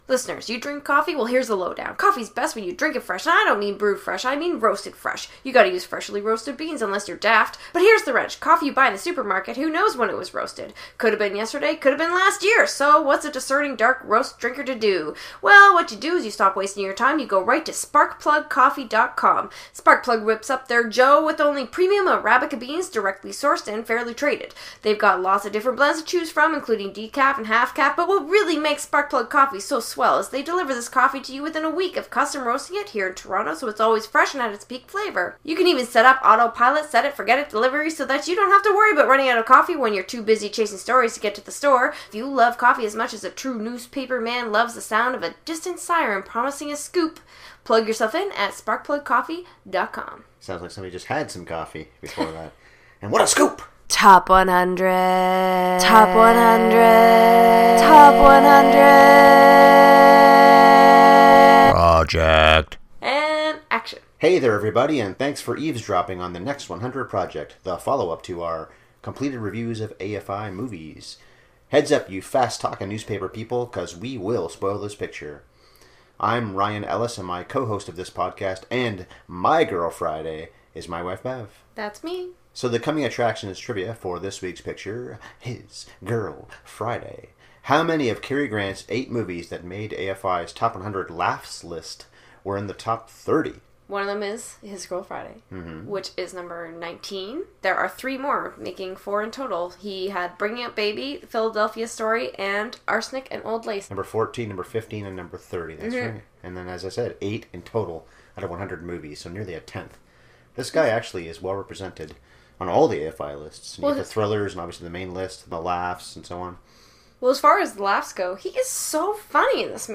Fire up His Girl Friday (29:57) Cover any sensitive ears. We swear a bit in this 'un. A comedy classic with Cary Grant & Rosalind Russell is #17 in the Next 100 Project.